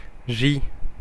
Ääntäminen
Ääntäminen France - Paris Tuntematon aksentti: IPA: /ʒi/ Haettu sana löytyi näillä lähdekielillä: ranska Käännöksiä ei löytynyt valitulle kohdekielelle.